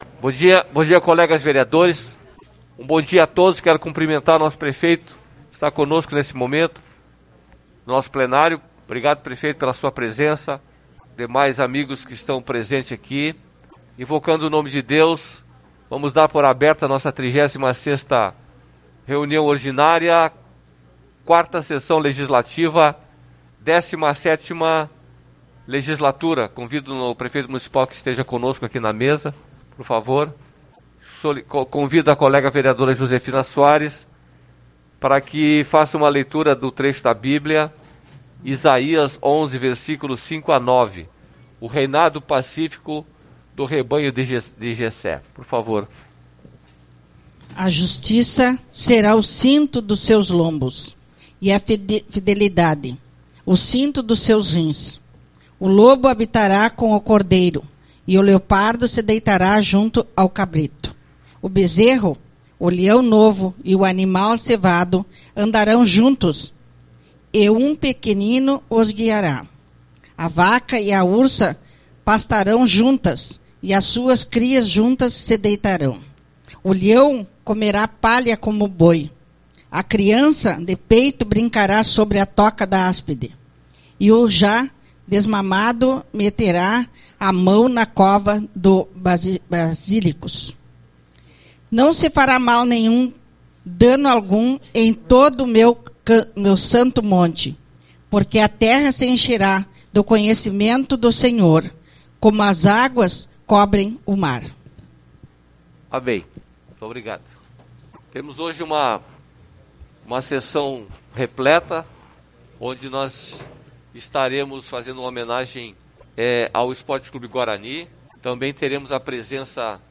14/07 - Reunião Ordinária